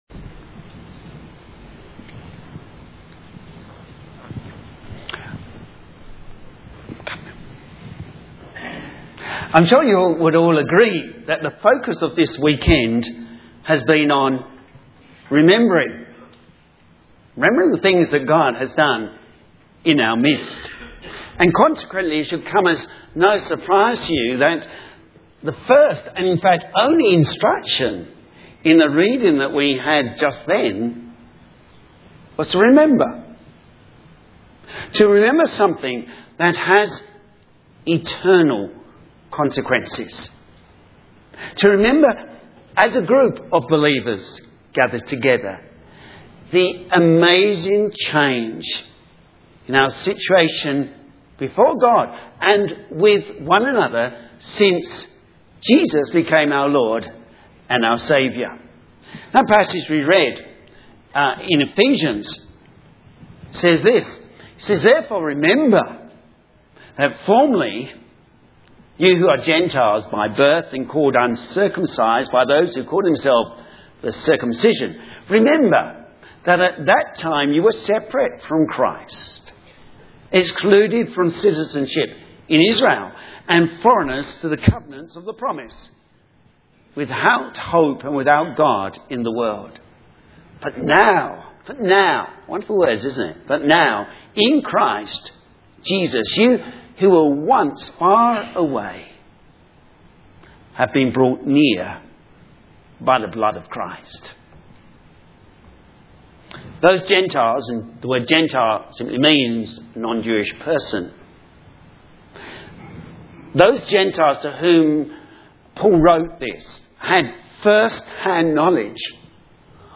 Sermon
Building Together The Dwelling Place Of God Ephesians 2:11-22 Synopsis This message was part of a celebration of our new buildings at the church and it lead us to consider that the greatest building we are part of it the Spiritual Temple which is God's Church.